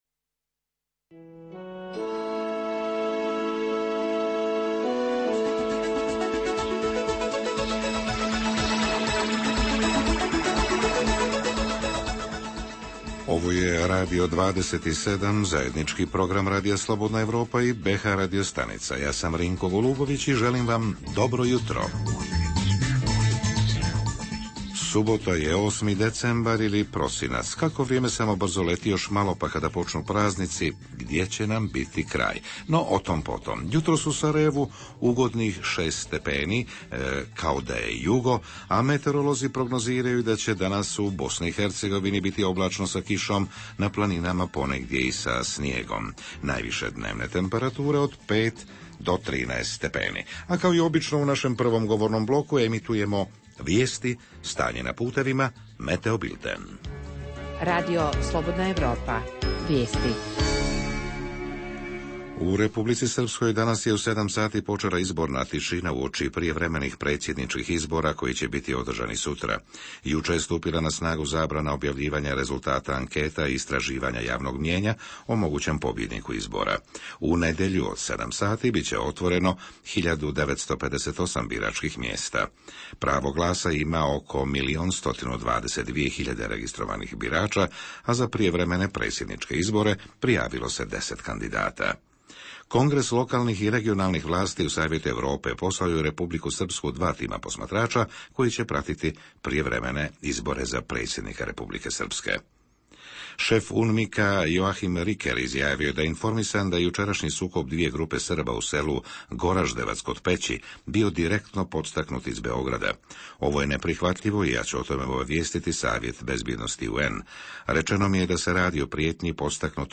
Radio 27: javljanja "u živo" iz Mostara i Bijeljine; vikend